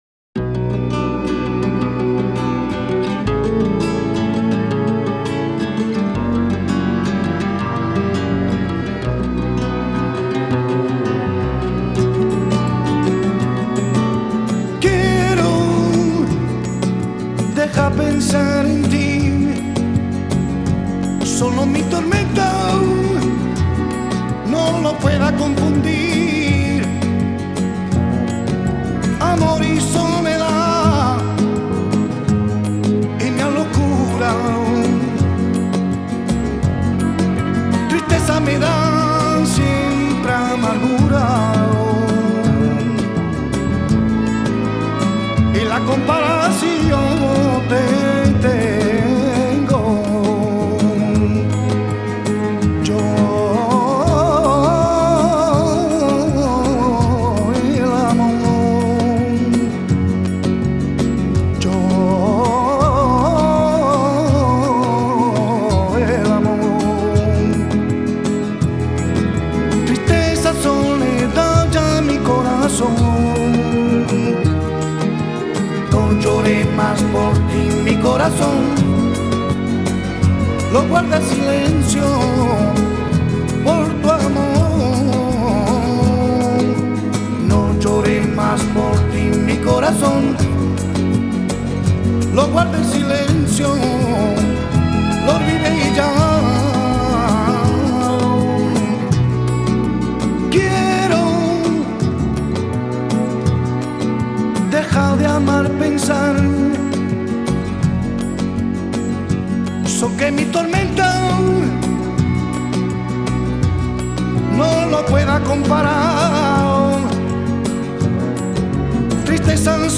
Латиноамериканская